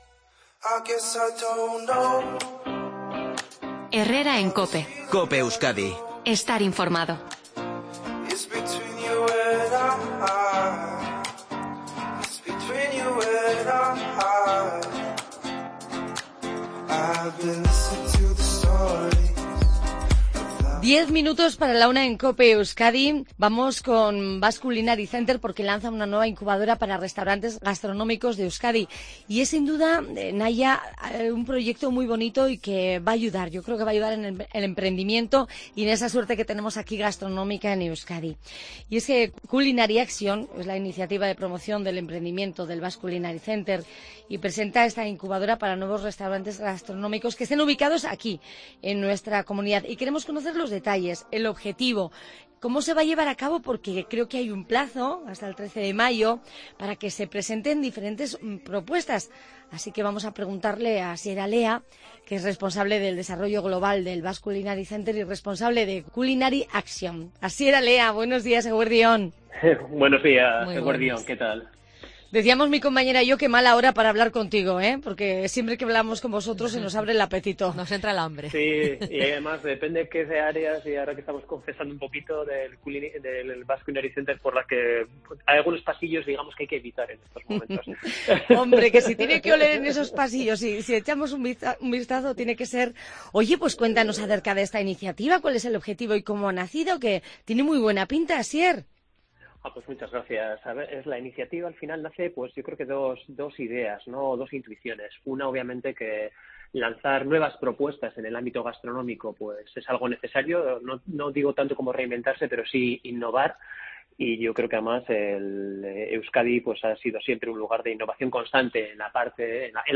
Puedes escuchar la entrevista clickando junto a la imagen y además te añadimos aquí la información sobre el proyecto: